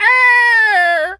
pgs/Assets/Audio/Animal_Impersonations/seagul_squawk_hurt_04.wav at master
seagul_squawk_hurt_04.wav